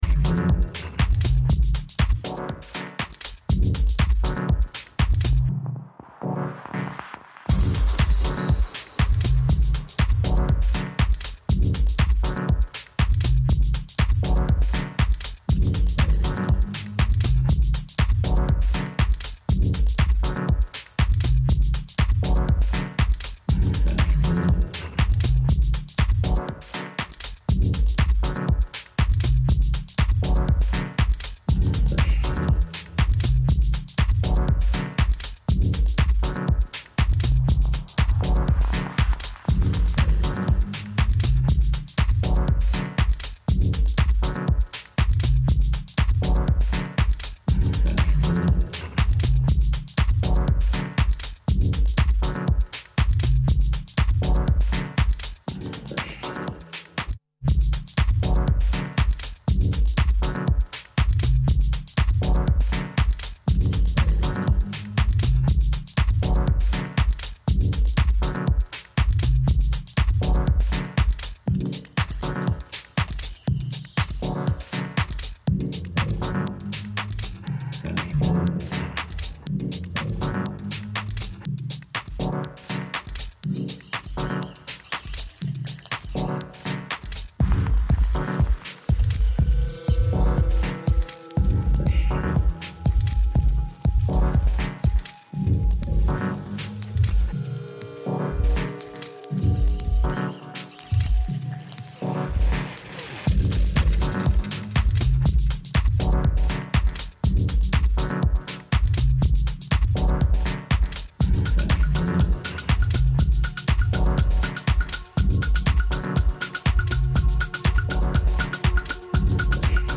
Also find other EDM Livesets,
Liveset/DJ mix